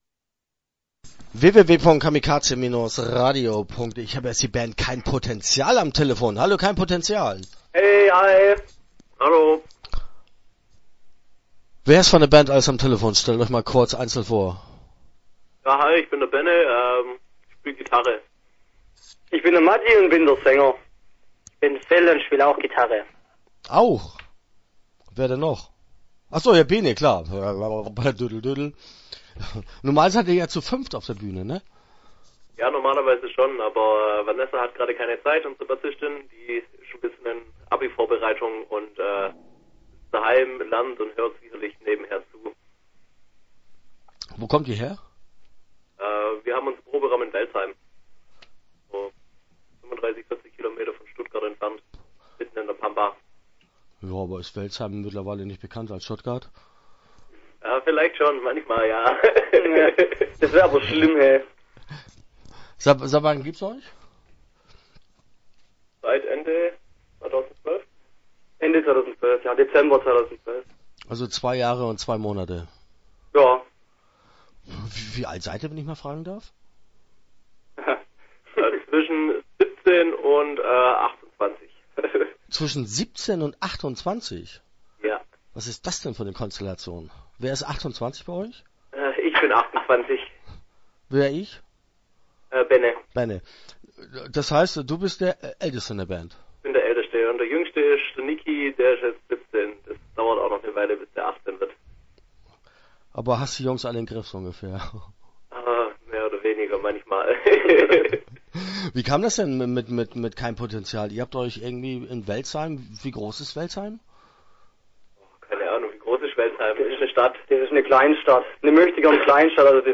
Start » Interviews » kein Potenzial